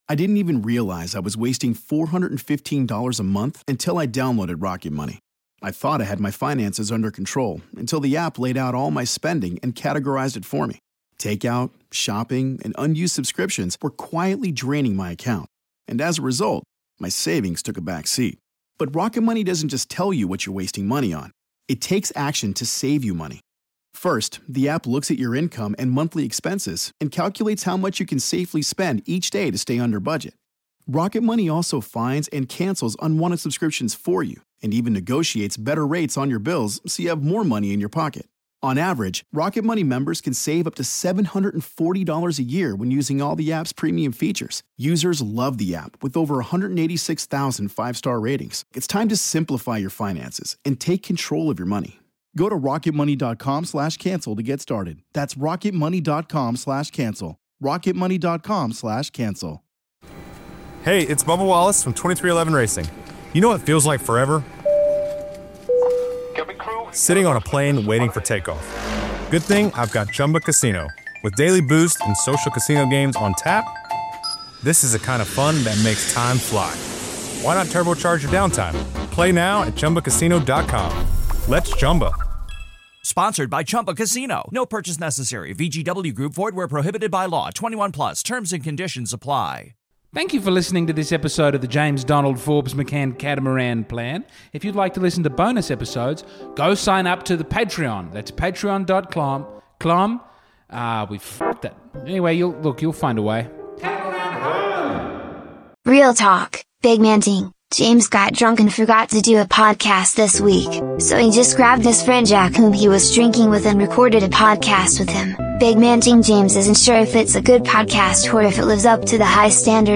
Mostly, thanks to my daughter for the music she wrote with her Grandpa.